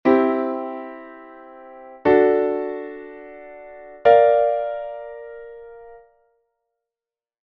Son acordes formados por 3 notas, a distancias de 3ª e 5ª dende a primeira nota.
DO-MI-SOL; RE-FA#-LA; SIb-RE-FA
acordes_triada_ejemplo.mp3